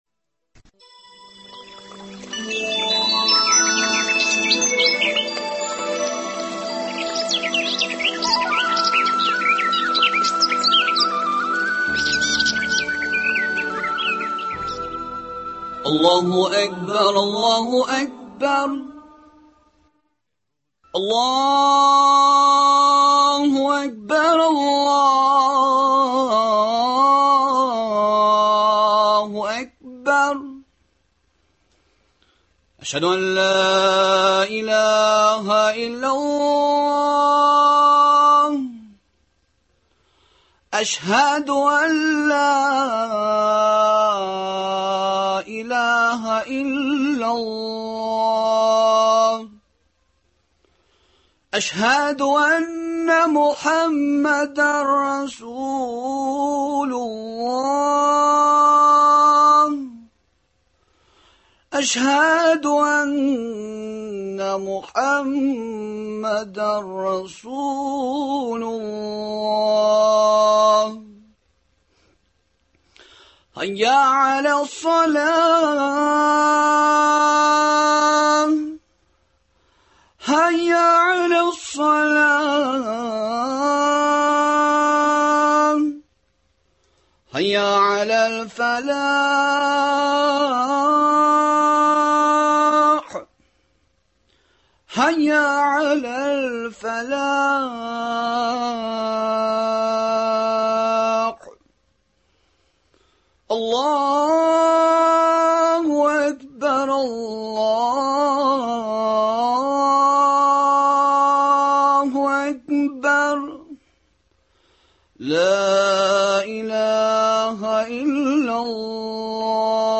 Боларның барысын да әлеге әңгәмәдән белә аласыз.